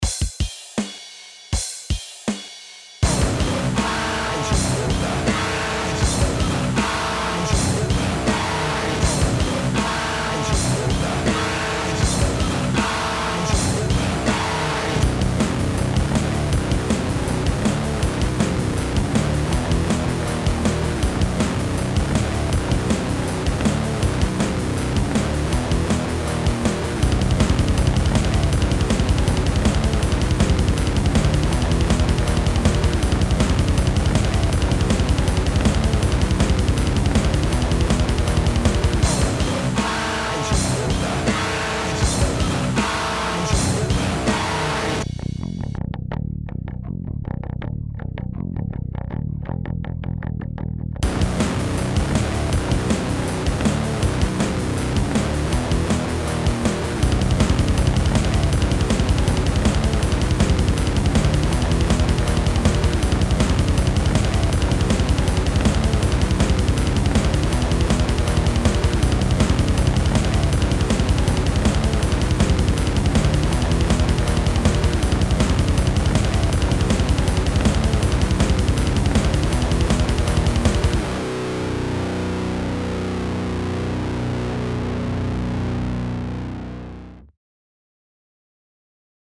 Metal was creeping back into my musicality.